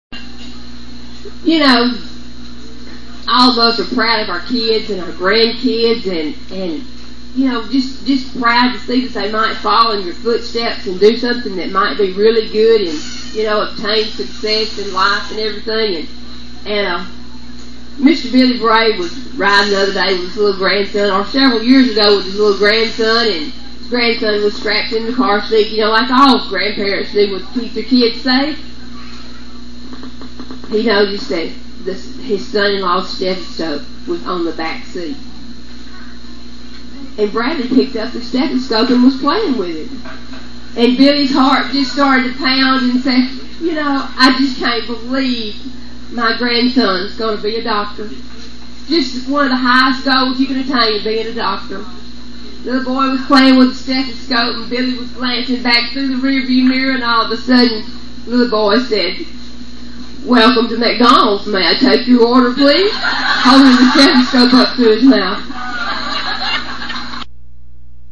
VFD Firehouse Jamboree Story!